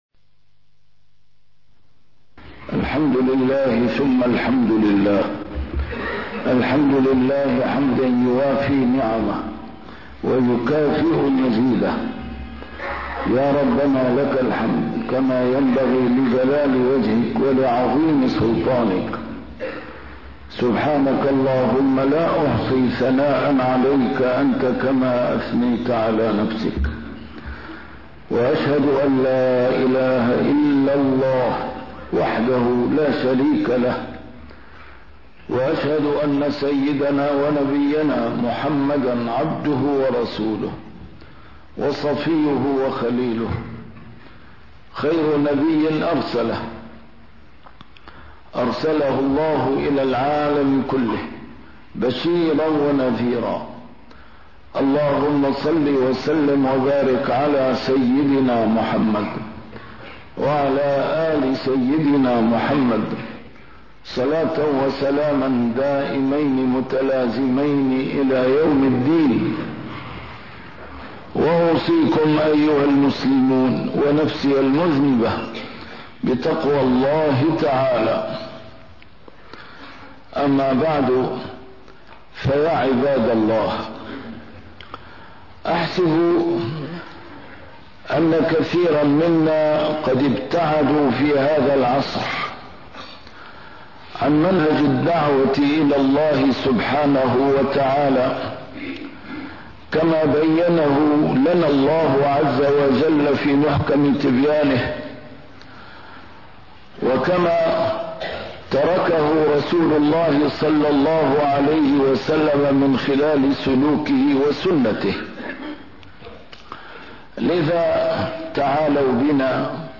A MARTYR SCHOLAR: IMAM MUHAMMAD SAEED RAMADAN AL-BOUTI - الخطب - الحوار منطلق قرآني في الدعوة إلى الله